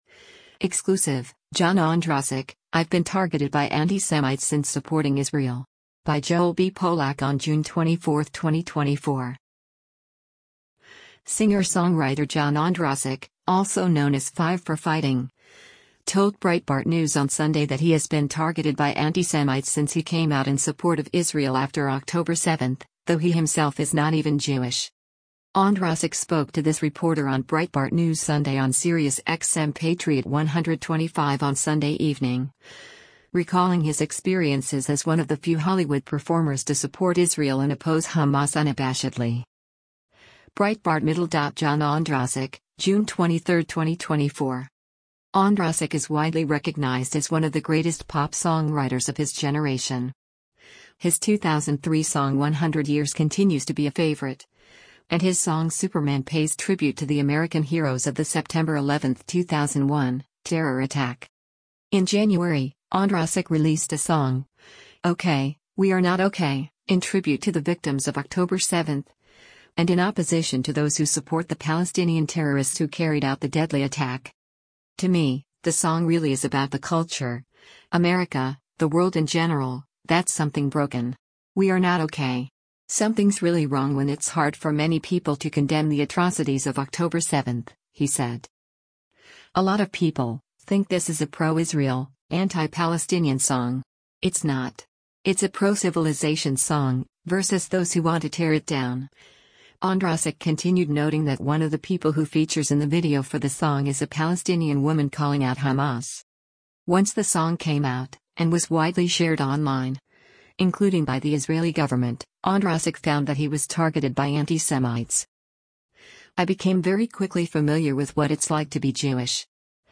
Singer-songwriter John Ondrasik, also known as Five for Fighting, told Breitbart News on Sunday that he has been targeted by antisemites since he came out in support of Israel after October 7 — though he himself is not even Jewish.
Ondrasik spoke to this reporter on Breitbart News Sunday on SiriusXM Patriot 125 on Sunday evening, recalling his experiences as one of the few Hollywood performers to support Israel and oppose Hamas unabashedly.